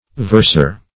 verser - definition of verser - synonyms, pronunciation, spelling from Free Dictionary Search Result for " verser" : The Collaborative International Dictionary of English v.0.48: Verser \Vers"er\ (v[~e]rs"[~e]r), n. A versifier.